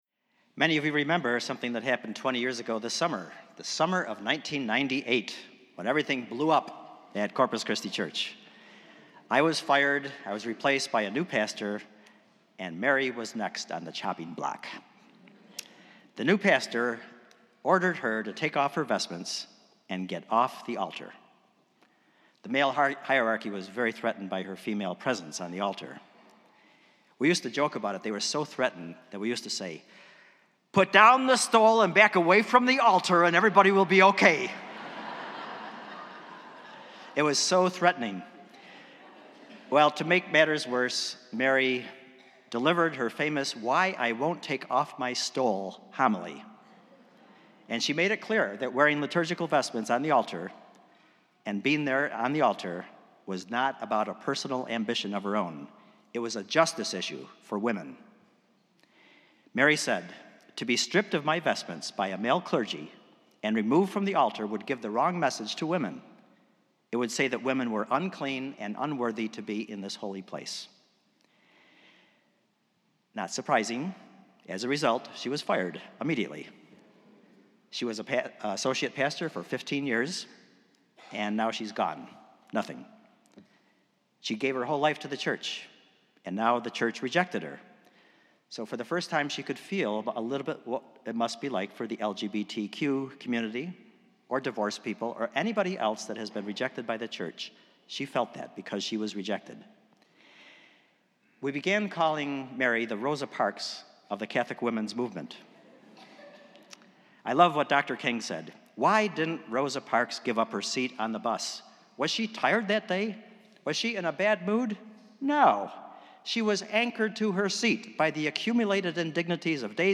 Spiritus Christi Mass June 3rd, 2018
A baby is baptized. The Spirit Singers offer up extraordinary music.